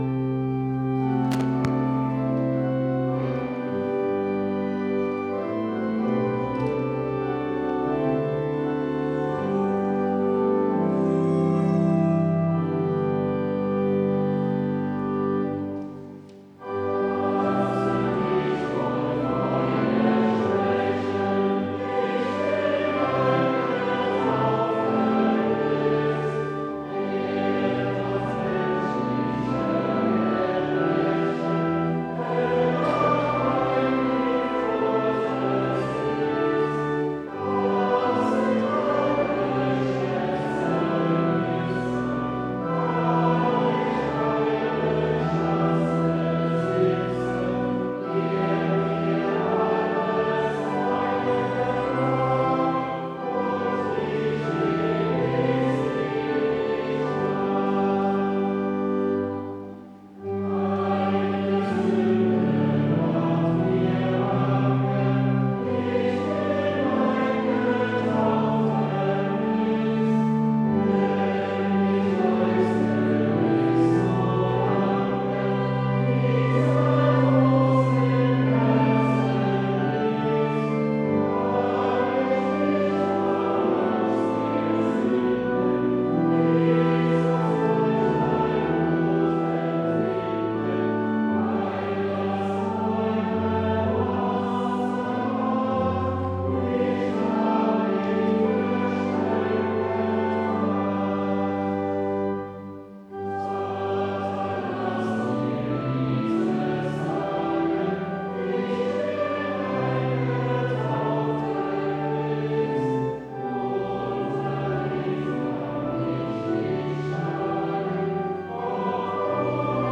Audiomitschnitt unseres Gottesdienstes vom 3. Sonntag nach Trinitatis 2025.